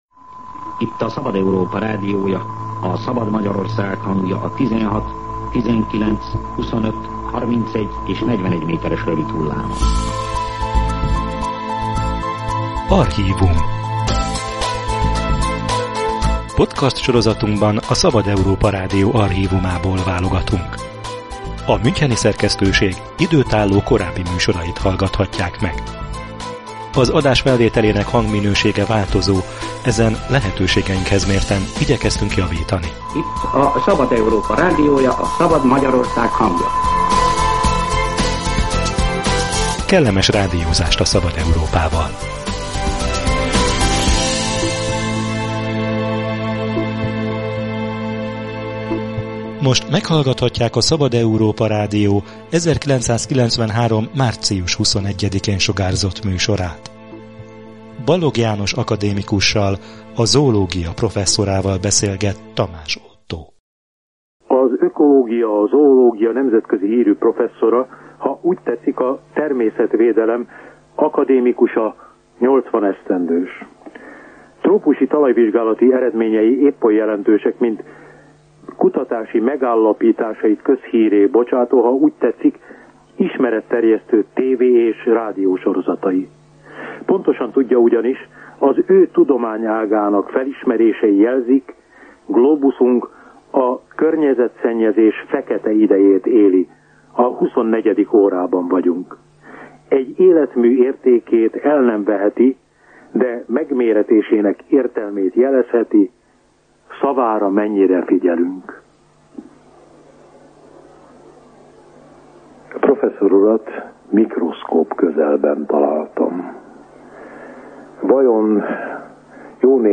Először az erdő szempontjából következik be a katasztrófa, később áttételesen az ember szempontjából is – figyelmeztetett az élővilág pusztításának veszélyeire már 1993-ban Balogh János zoológus professzor. A Szabad Európa Rádió az akadémikus 80. születésnapja alkalmából kérte az interjút.